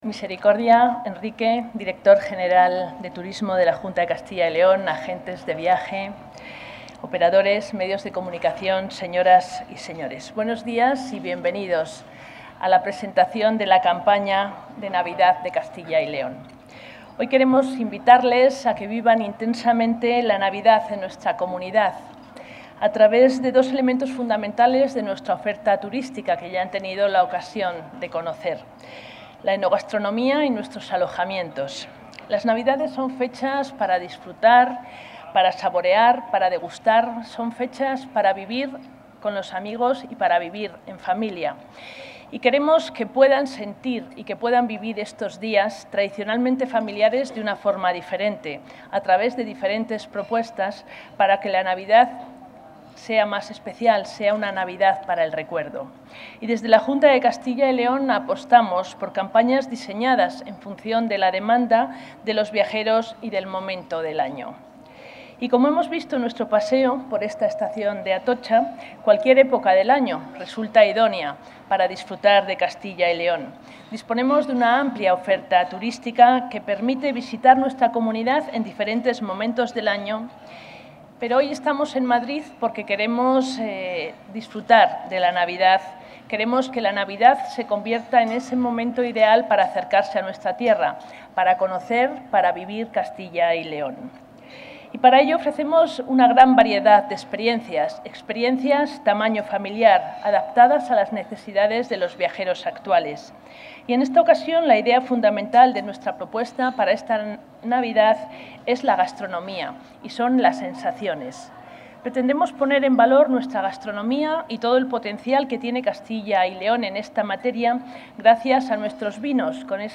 La consejera de Cultura y Turismo, María Josefa García Cirac, ha presentado en la estación de Atocha de Madrid la campaña...
Intervención de la consejera de Cultura y Turismo.